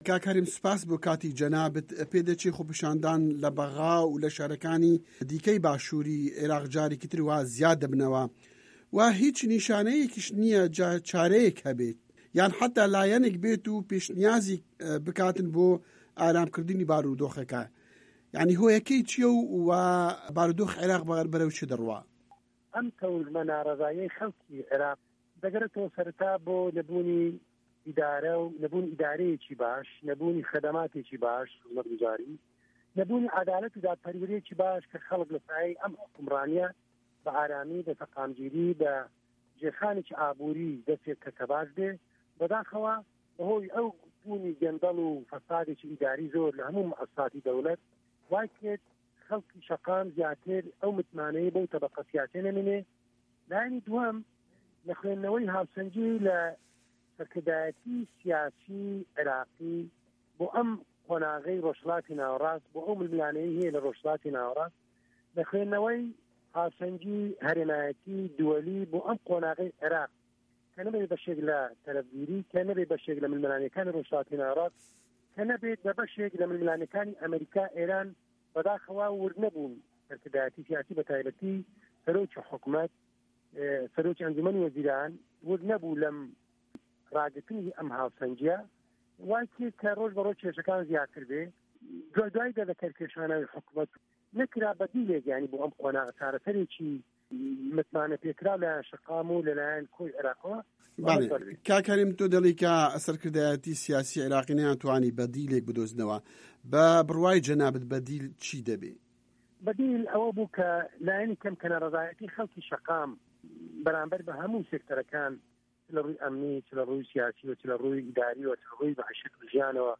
وتووێژ لەگەڵ هەرێم کەمال ئەغا